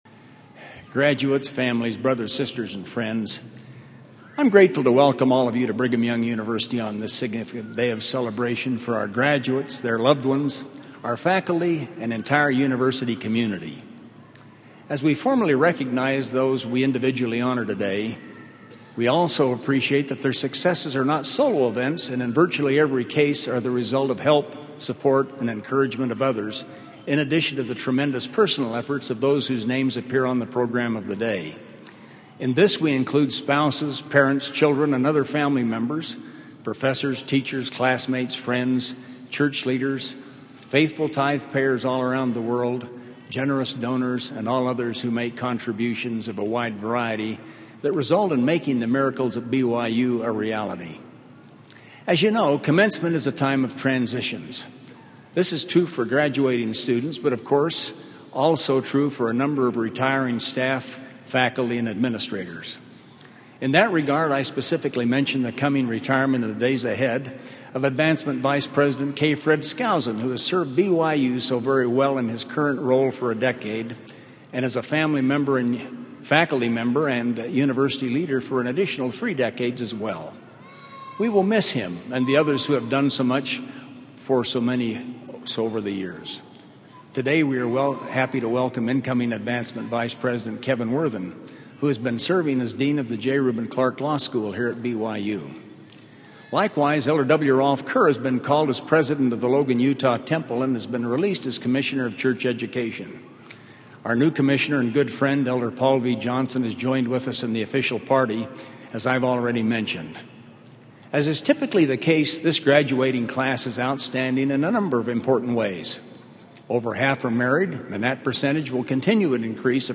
Audio recording of A Time of Transitions by Cecil O. Samuelson
President of Brigham Young University